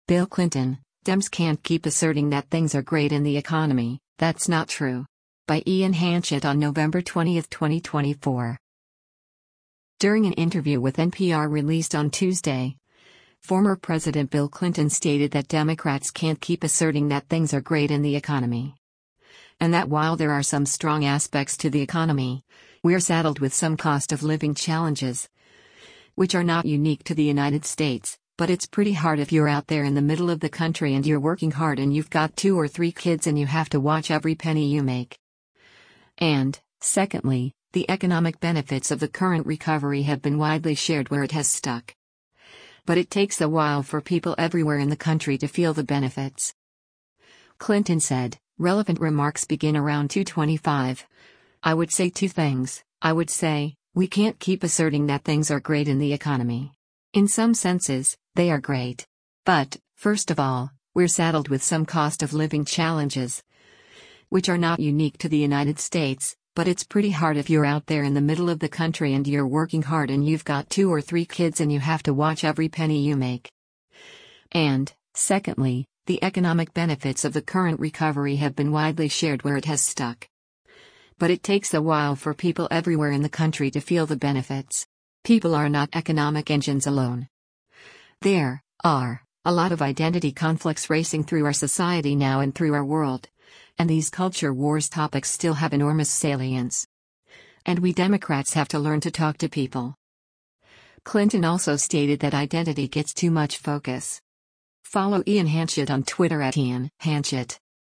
During an interview with NPR released on Tuesday, former President Bill Clinton stated that Democrats “can’t keep asserting that things are great in the economy.”